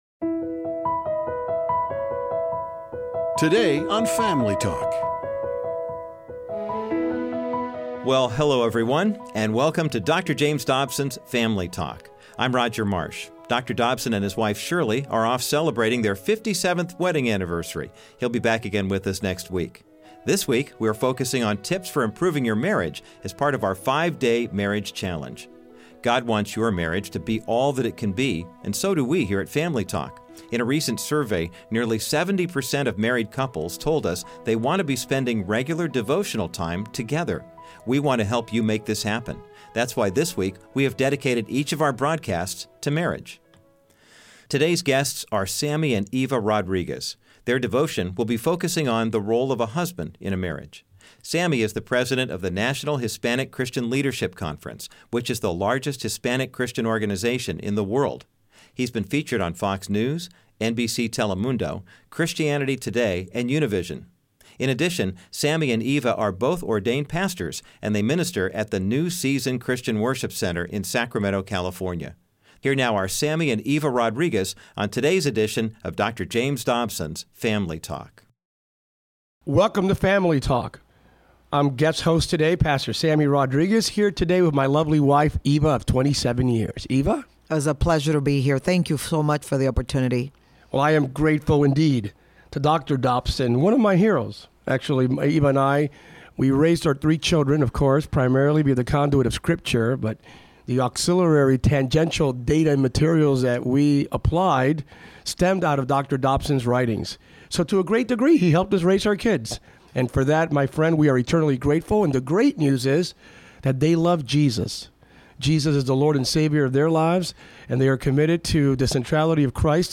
Based on this book, Family Talk is offering listeners the 5-Day Marriage Challenge. Guest speakers